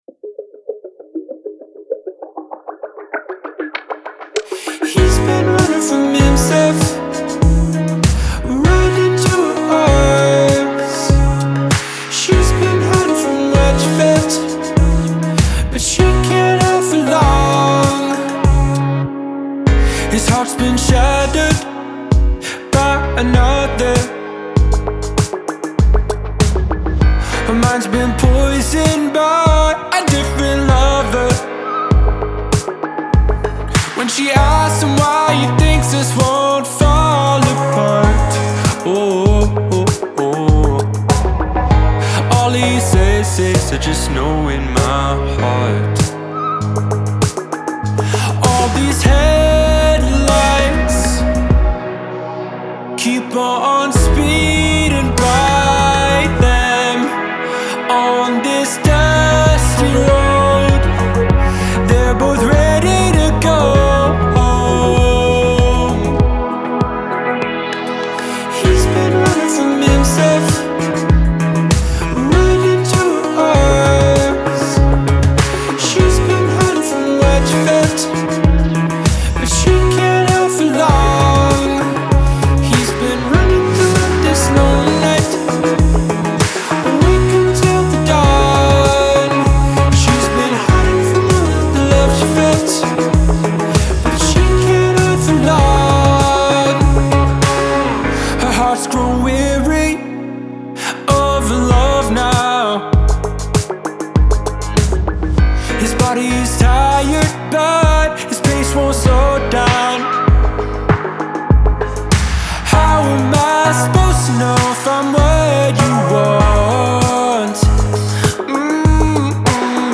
atmospheric pop
well crafted, ambient, electro-infused pop jams